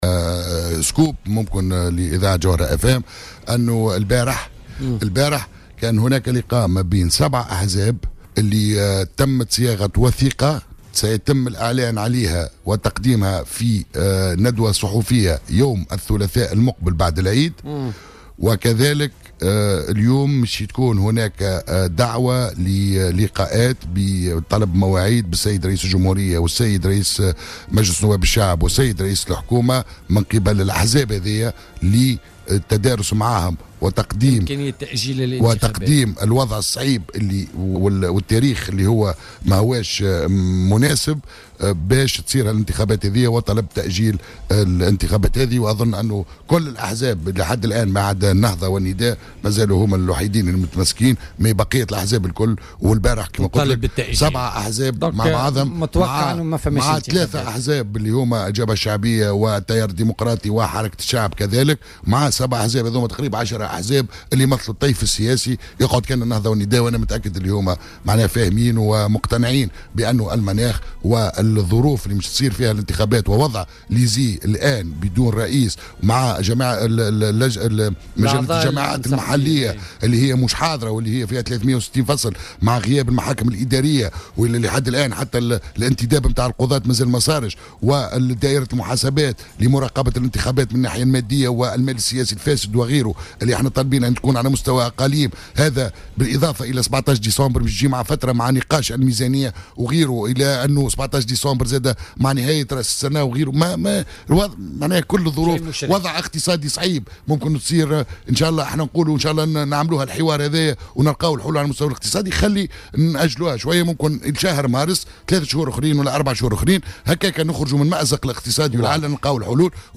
وقال ضيف "بوليتيكا" على "الجوهرة أف أم" إن 7 أحزاب عقدت لقاء منذ يومين لصياغة وثيقة رسمية حول تأجيل الانتخابات البلدية كما سيتم تنظيم ندوة صحفية الثلاثاء المقبل لإعلان هذه الوثيقة بشكل رسمي.